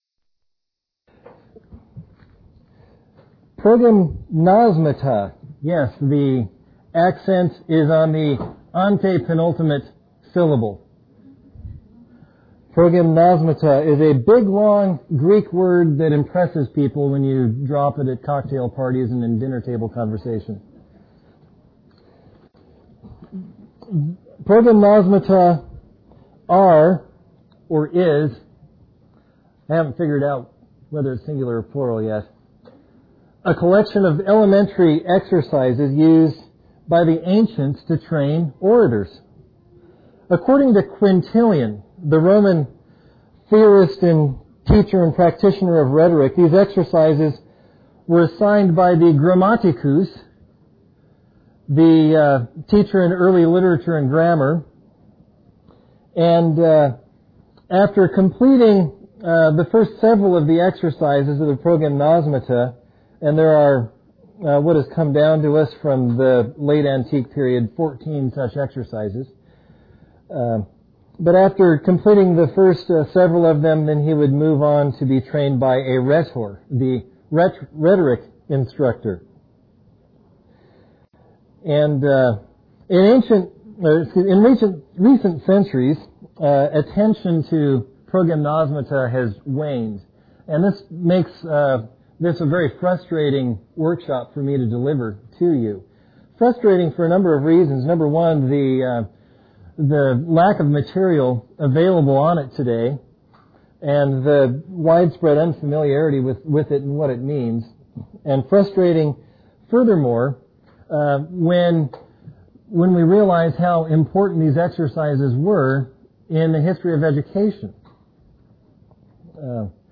2004 Workshop Talk | 0:59:51 | 7-12, Rhetoric & Composition